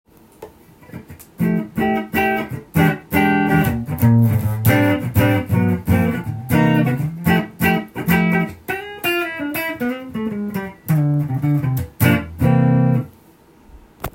３３５タイプのセミアコです。
弦はジャズ弦を張っていますのでいますので、ジャズサウンドに合う丸い音がします。
試しに弾いてみました
鳴りますね！ボディーとネックの一体感を感じる職人の技を感じます。
フロントピックアップで弾いているのですが